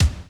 上記動画の1:08頃から始まるバスドラムの音を考えてみよう．
drum1.wav